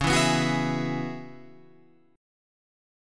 Dbm11 chord